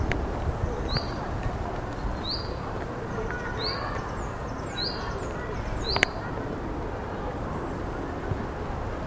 Fringilla coelebs
Nome em Inglês: Eurasian Chaffinch
Fase da vida: Adulto
Localidade ou área protegida: Villa borghese
Condição: Selvagem